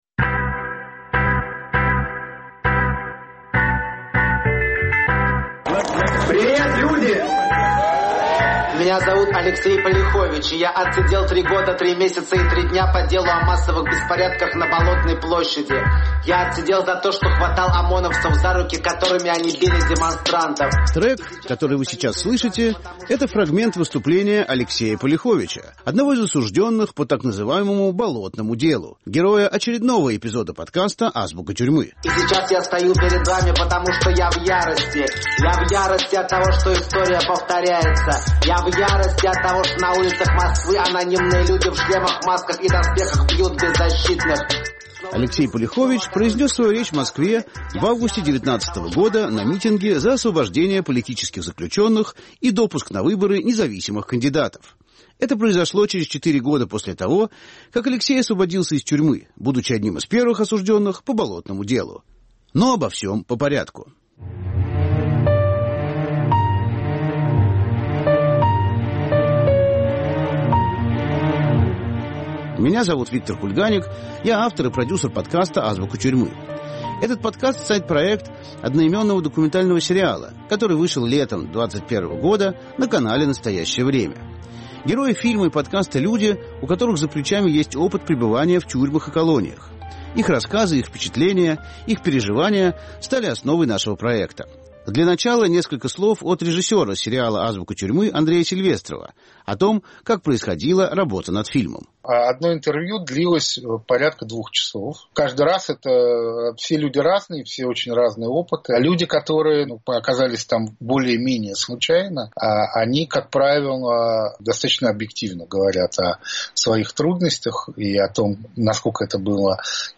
Повтор эфира от 1 мая 2022 года.